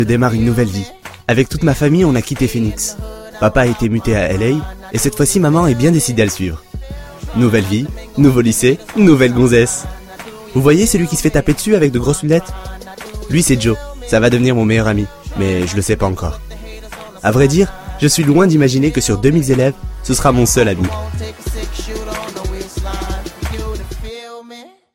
Bandes-son
Voix off
- Baryton